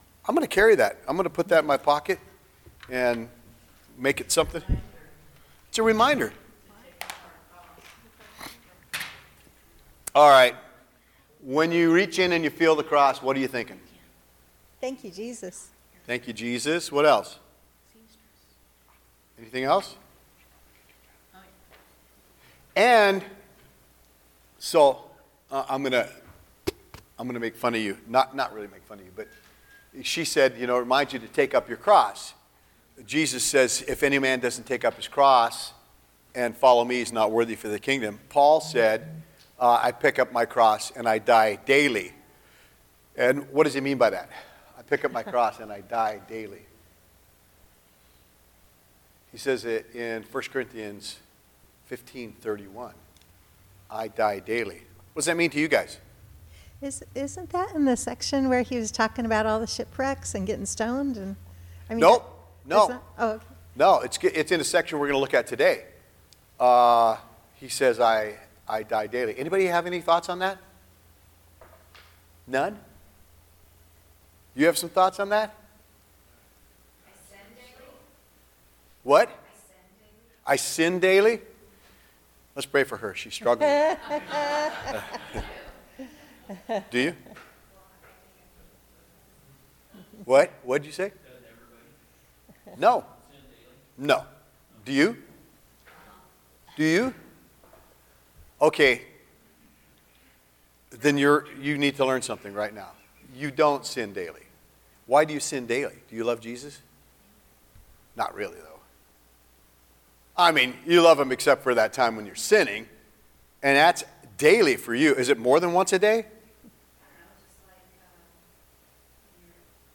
04-21-25 PRE-SERMON TALK | The Way Church Sutherlin